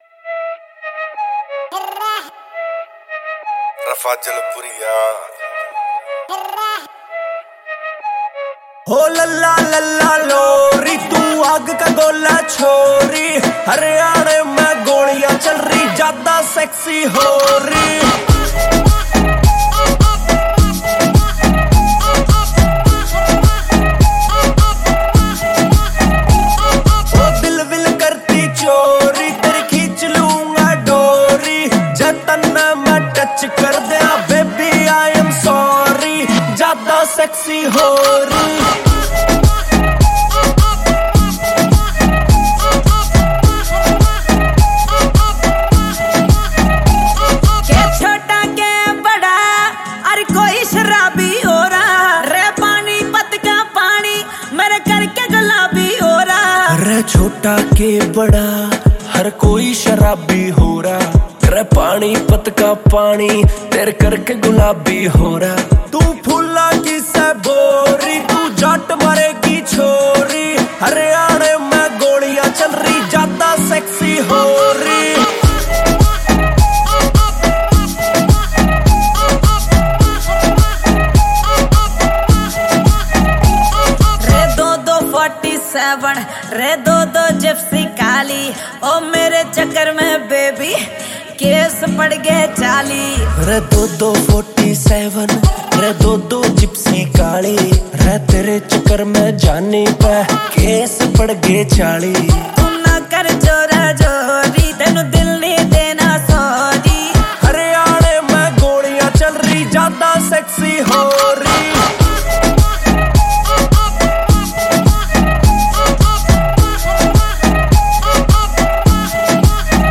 Haryanvi Mp3 Songs